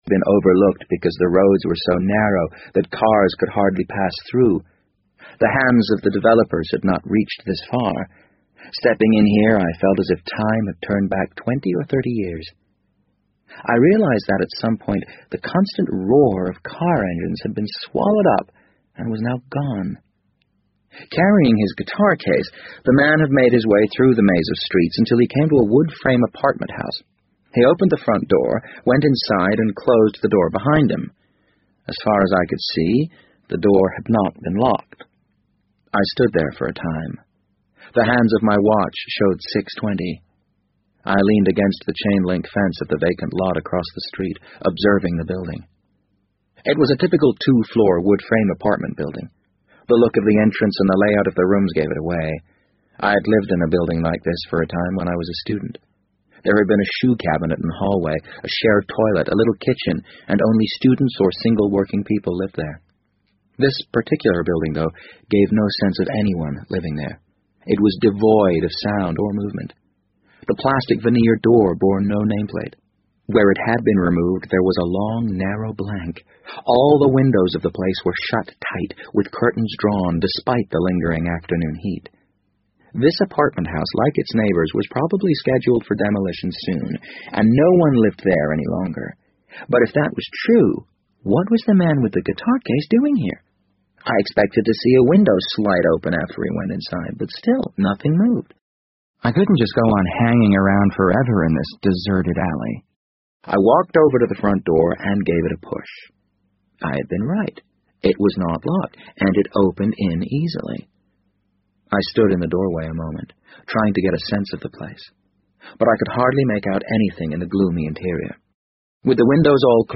BBC英文广播剧在线听 The Wind Up Bird 009 - 3 听力文件下载—在线英语听力室